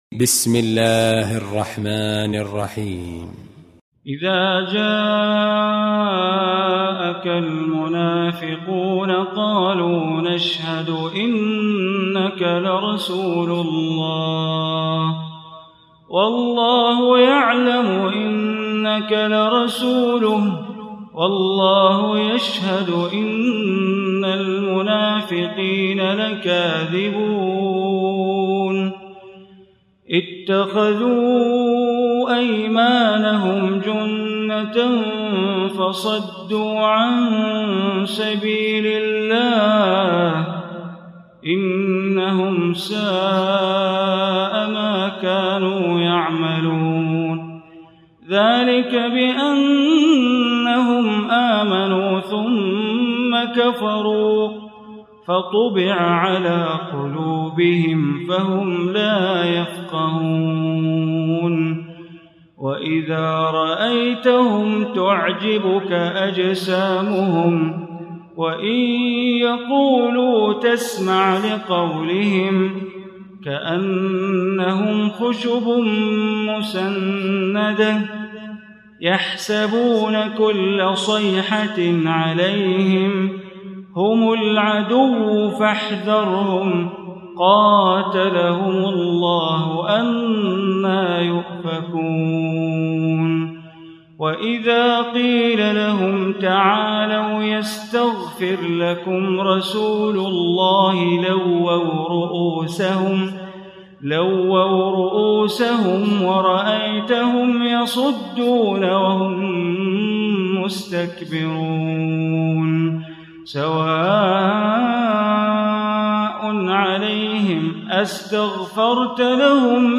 Surah Munafiqun Recitation by Sheikh Bandar
Surah Al-Munafiqun, listen online mp3 tilawat / recitation in Arabic recited by Imam e Kaaba Sheikh Bandar Baleela.